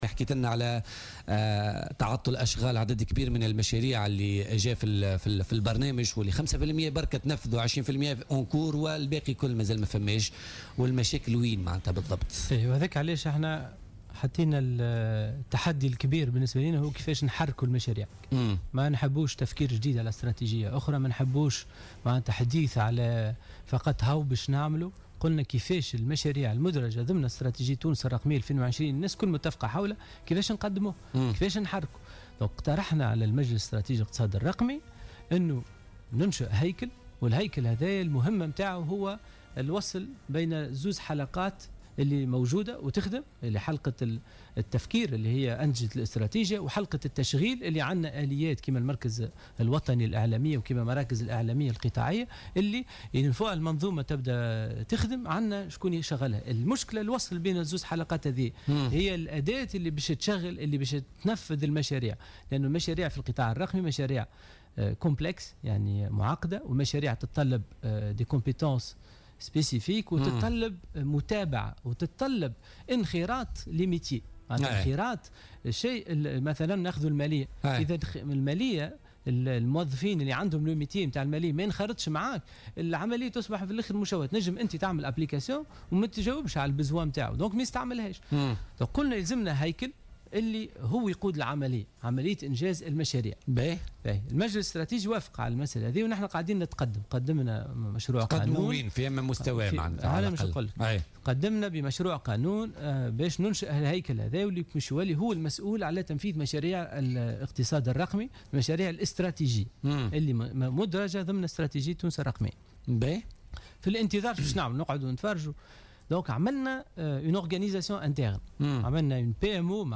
أكد محمد أنور معروف وزير تكنولوجيا المعلومات و الاتصال الرقمي ضيف بوليتيكا اليوم الثلاثاء 25 جويلية 2017 أن التحدي الكبير لوزارته كان يتمثل في كيفية تسريع انجاز المشاريع المعطلة المدرجة ضمن استراتيجية تونس الرقمية سنة 2020.